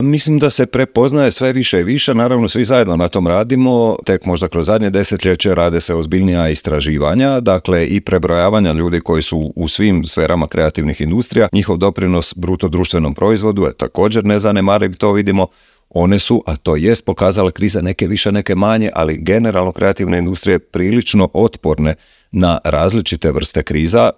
Tim povodom u intervjuu Media servisa